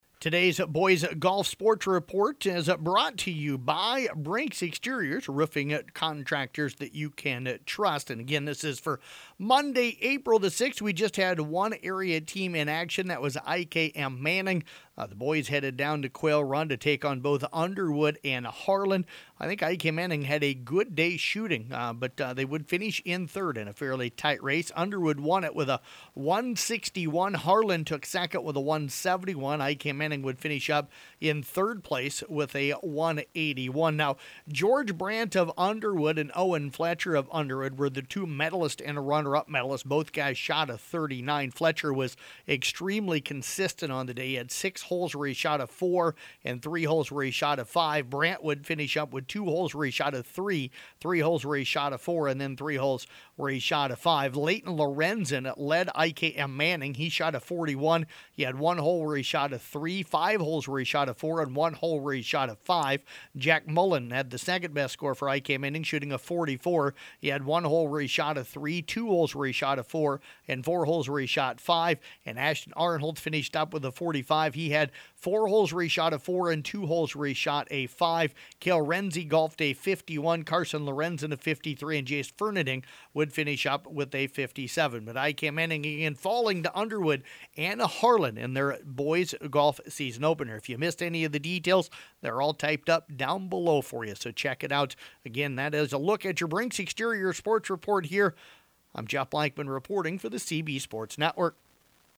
Below is an audio report for Boys Golf on Monday, April 6th